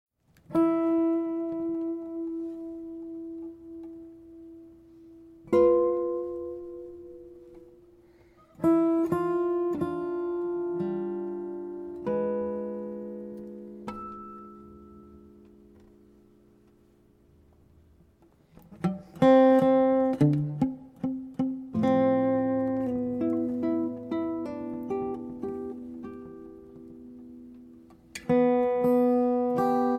Two Master Guitarists + 18 tracks = Acoustic Improv Heaven
The improvised duet is a singularly revealing format.